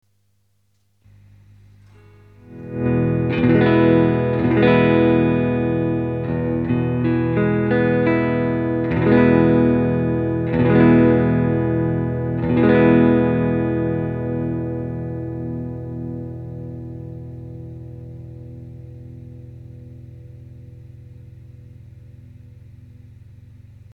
PUはネックDP-402、センターDP-117、リヤDP-403だば。
くぐもったリヴァーブがかったような
倍音成分の多い音だば。
■アンプ：Fender　Pro-Junior（15W)
■マイク：Seide　PC-VT3000/SHURE　SM57-LCE